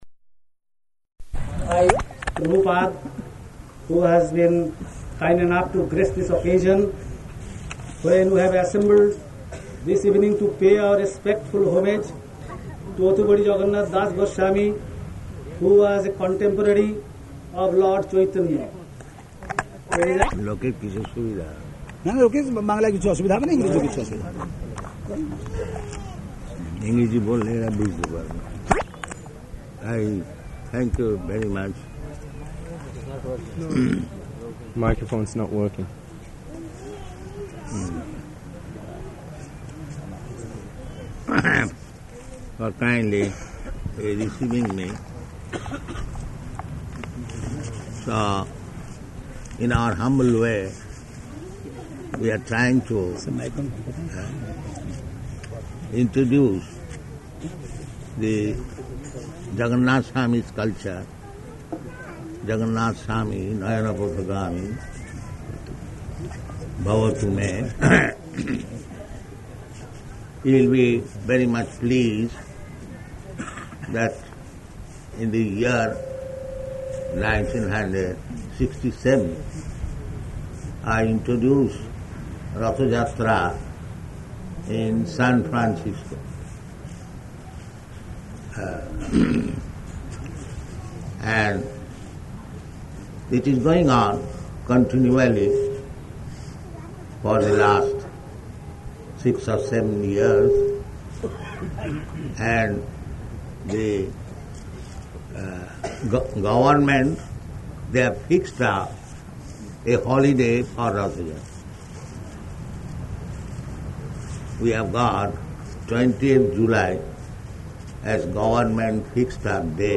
Type: Lectures and Addresses
Location: Jagannātha Purī